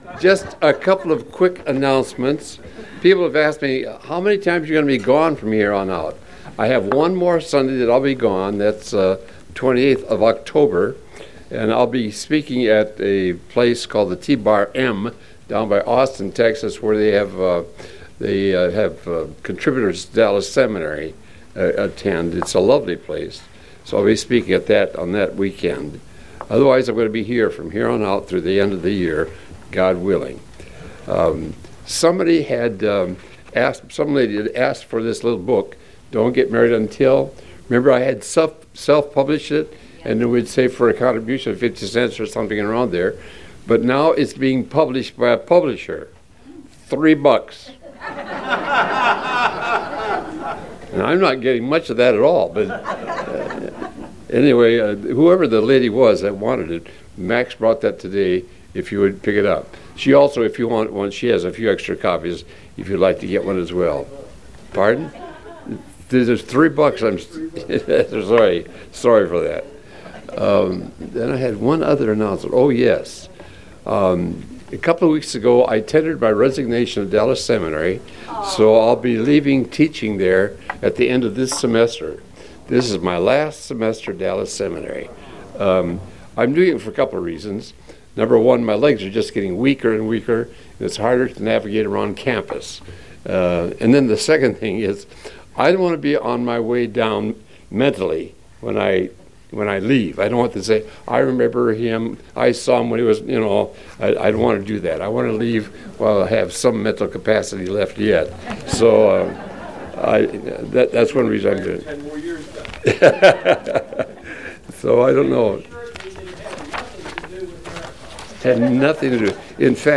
James Lesson 5: When Saints Become Snobs